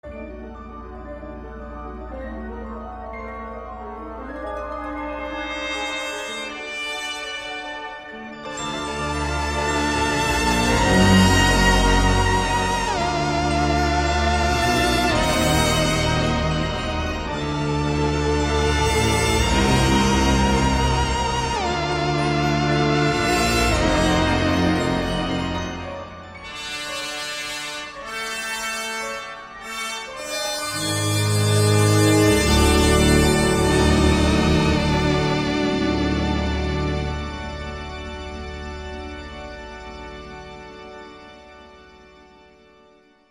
la musique sublime, expérimentale et intemporelle